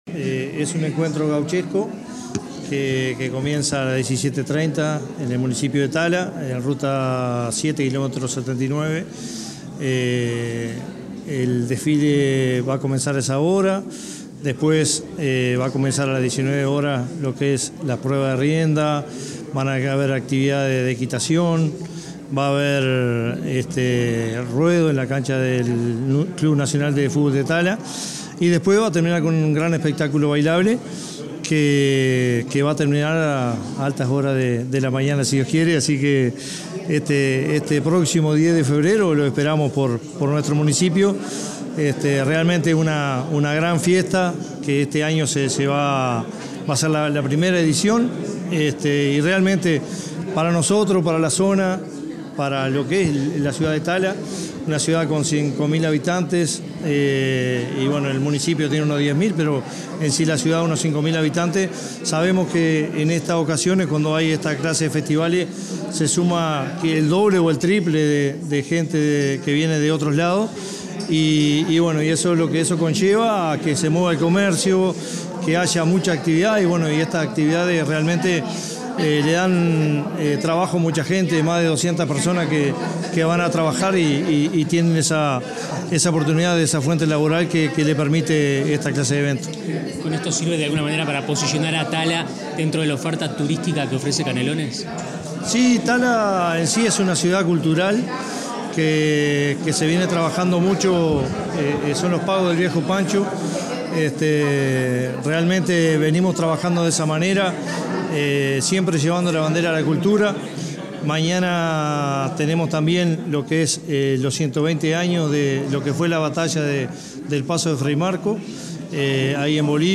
Declaraciones a la prensa del alcalde de Tala, Leonardo Pérez
Declaraciones a la prensa del alcalde de Tala, Leonardo Pérez 30/01/2024 Compartir Facebook X Copiar enlace WhatsApp LinkedIn Este 30 de enero, se realizó el acto de lanzamiento de la Noche Gaucha de Doma y Folclore, actividad que se desarrollará el 10 de febrero en el estadio Nacional de Tala, Canelones. Tras el evento, el alcalde Leonardo Pérez realizó declaraciones a la prensa.